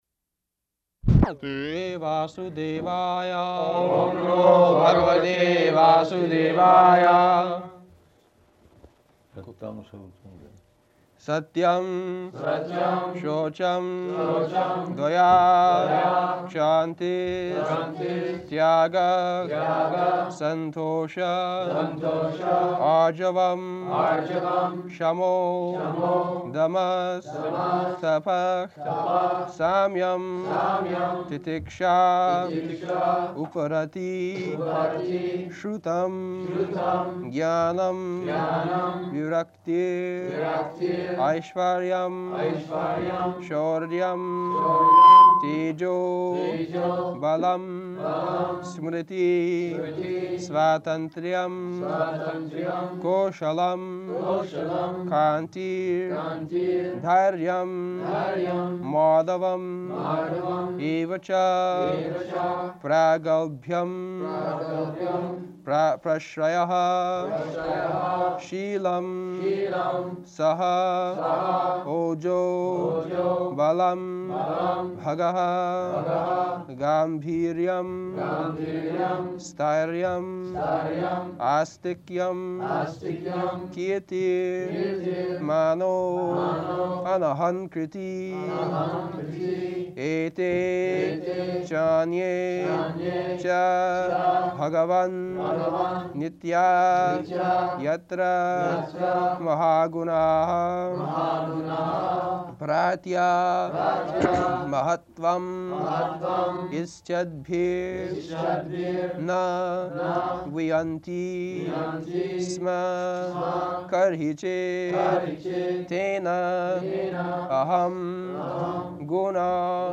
January 23rd 1974 Location: Honolulu Audio file
[devotees repeat] [leads chanting of verse] Prabhupāda: Satyaṁ śaucaṁ dayā kṣāntis.